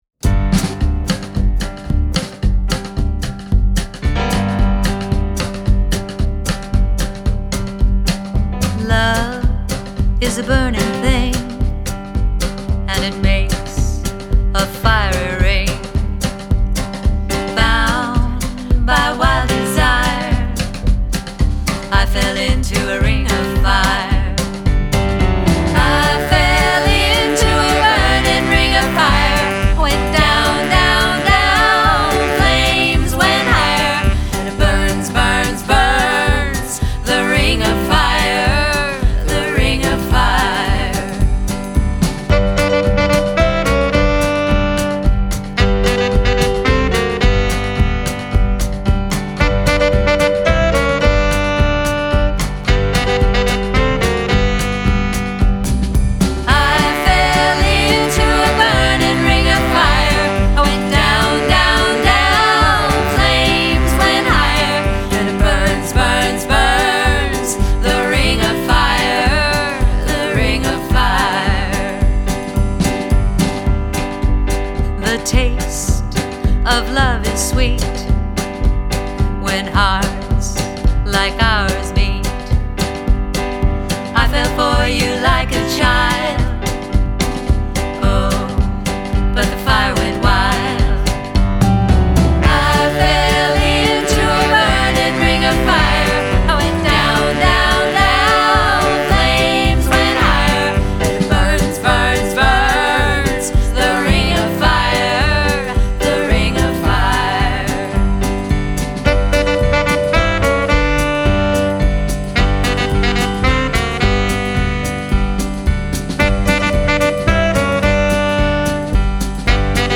began singing together in front of the Rockabilly Swing Band